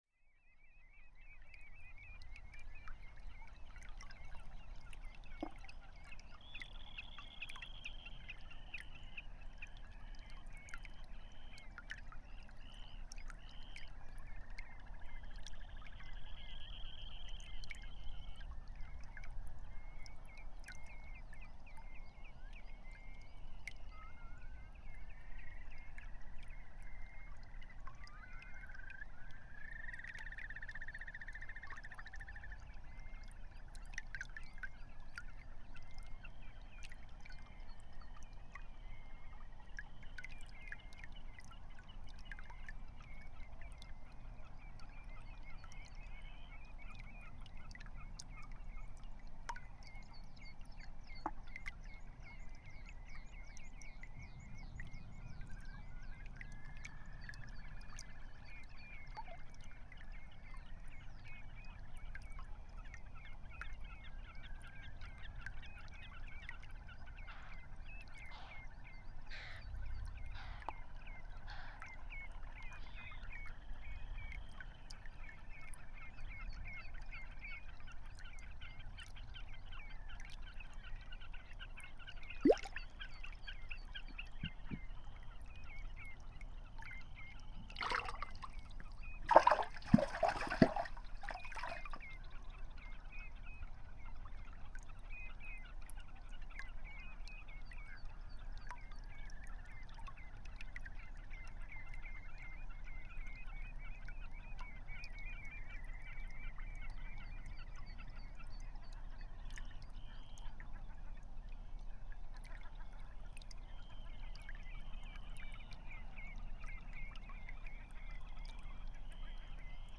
Imagine, It is 3:30, mid summer morning. You are within five kilometers from the arctic circle and two and a half kilometers from the north Atlantic ocean. It is calm, and dry and the sun which has never goes completely down this night, gives a silk smooth light through thin layer of clouds.
From bugs in the grass, fish in the brook to the birds in the air or in the field.